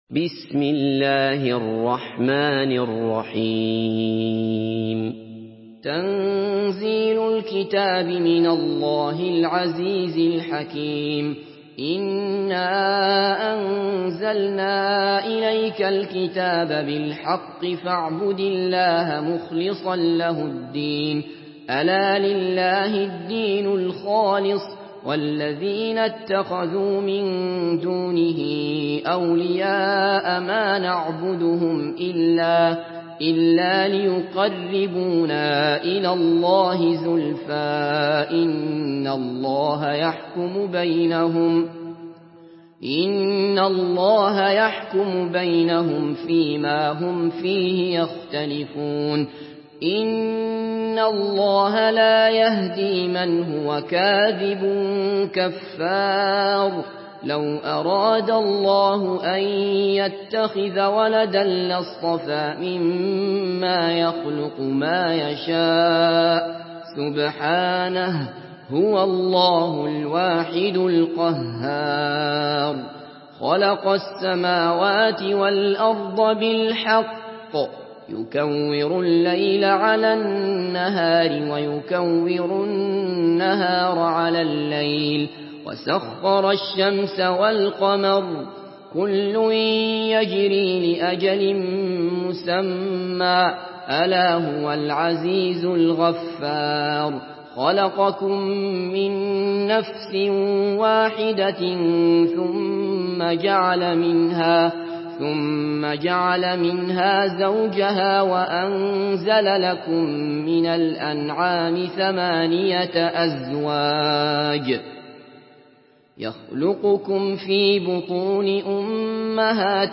Surah আয-যুমার MP3 by Abdullah Basfar in Hafs An Asim narration.
Murattal Hafs An Asim